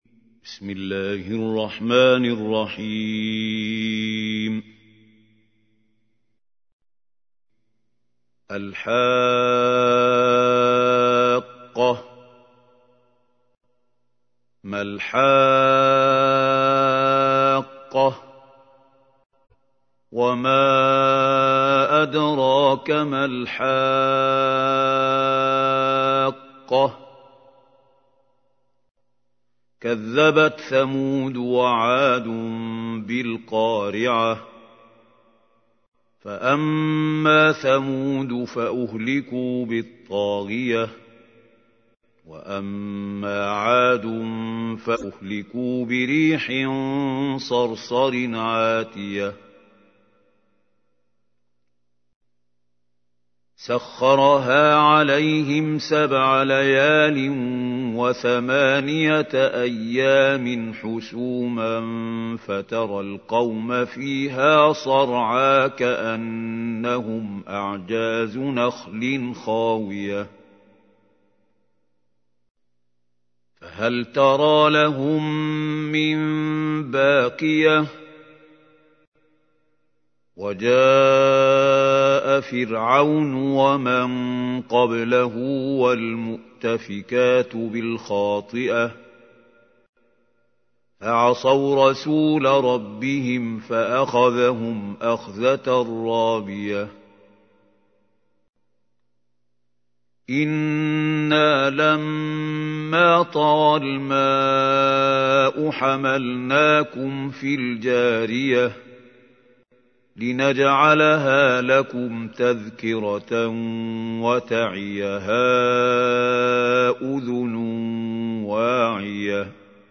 تحميل : 69. سورة الحاقة / القارئ محمود خليل الحصري / القرآن الكريم / موقع يا حسين